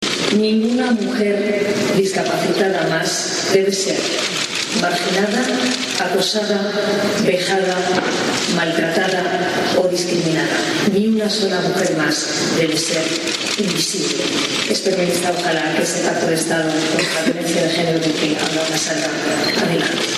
El Congreso fue clausurado por la reina Letizia, quien